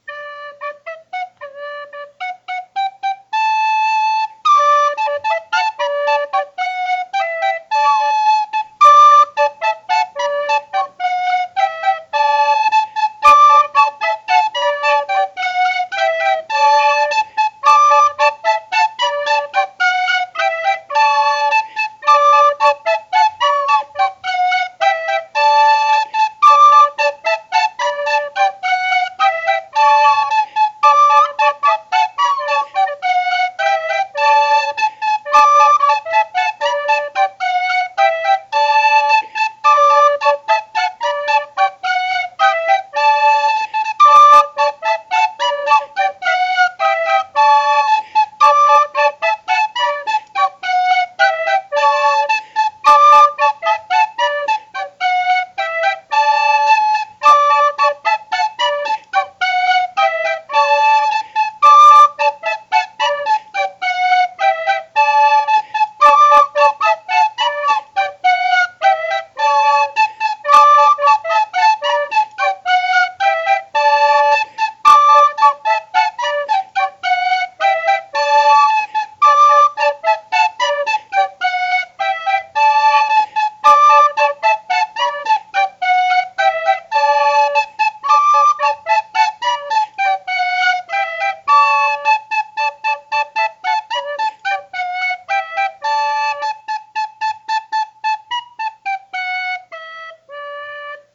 tin whistle
Words and music: English traditional